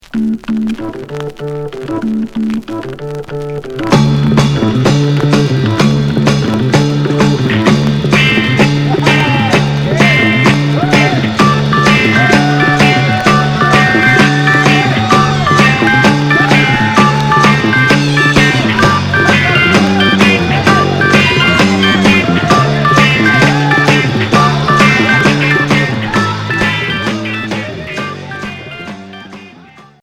R'n'b garage